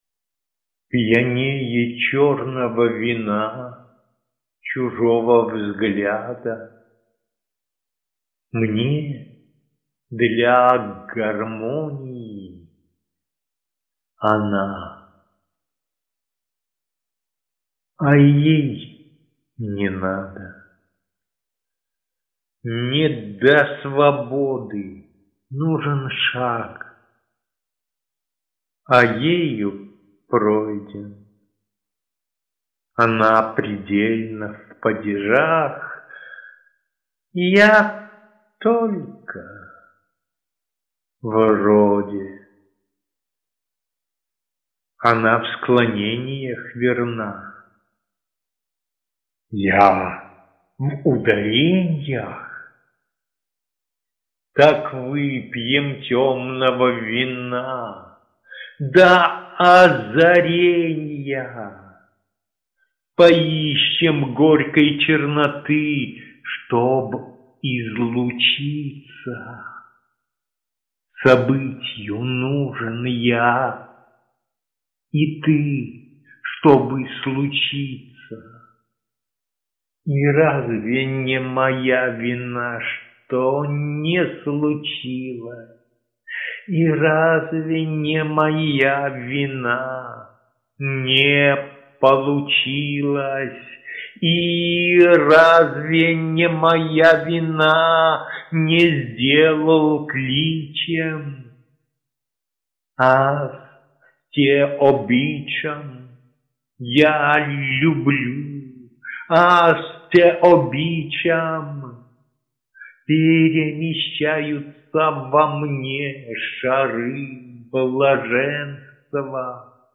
звучащие стихи